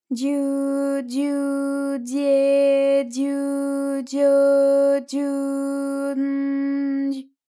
ALYS-DB-001-JPN - First Japanese UTAU vocal library of ALYS.
dyu_dyu_dye_dyu_dyo_dyu_n_dy.wav